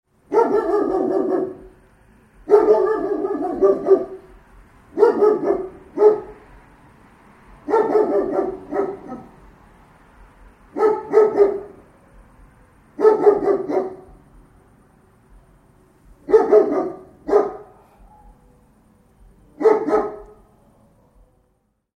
Big-guard-dog-barking-sound-effect.mp3